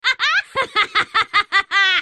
willowlaugh3.mp3